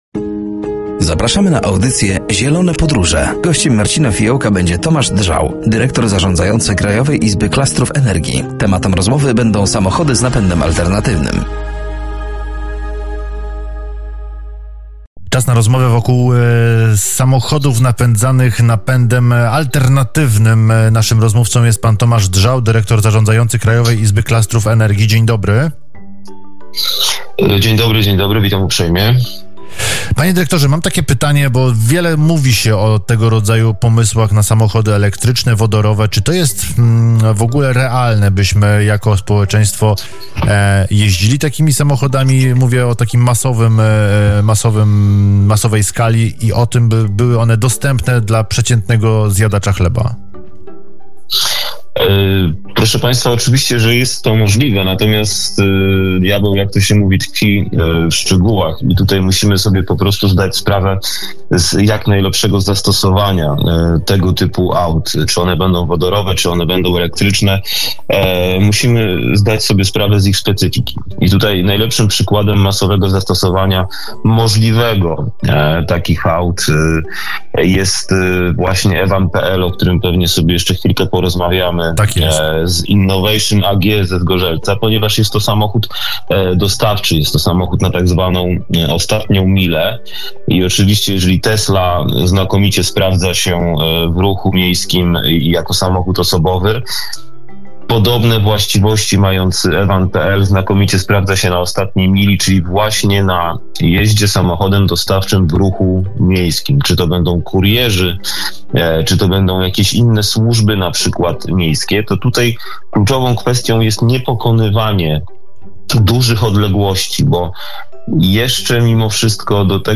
Rozmowa dotyczyła samochodów z napędem alternatywnym.